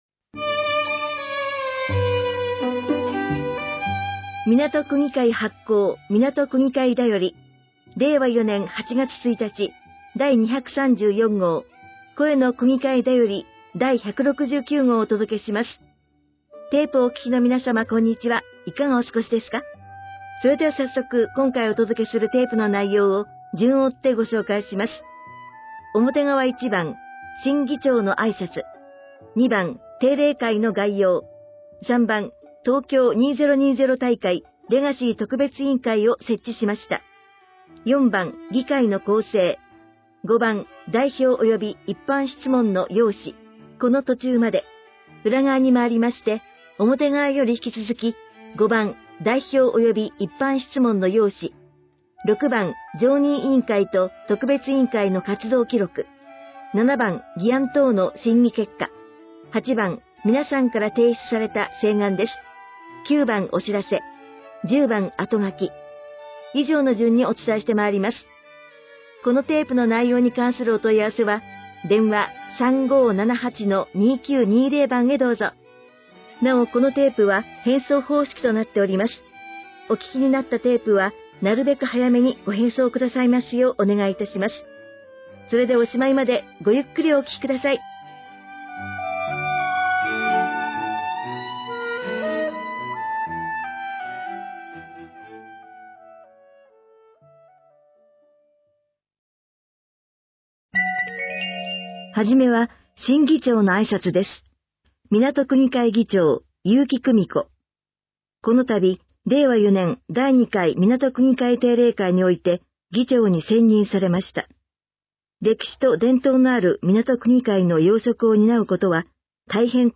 掲載している音声ファイルは、カセットテープで提供している音声ファイルをそのまま掲載しています。そのため、音声の冒頭で「テープの裏側にまいりました」のような説明が入っています。
（「A」、「B」はそれぞれカセットテープのA面とB面を表しています。）